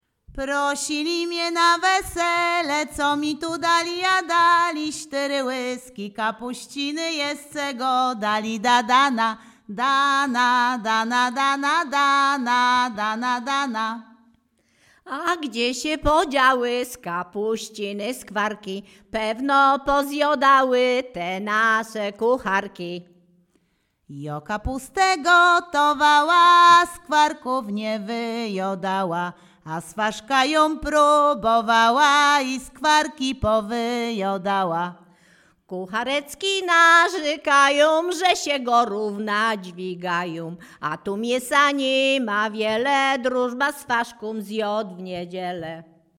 Przyśpiewki
miłosne weselne wesele przyśpiewki